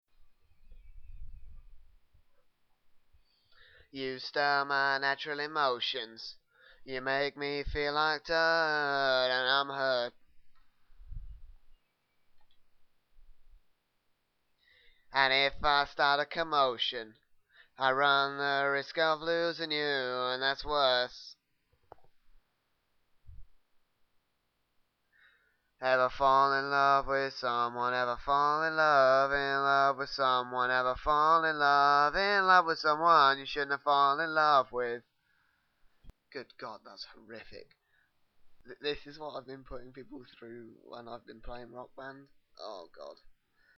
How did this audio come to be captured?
Original file, which was edited for being too quiet.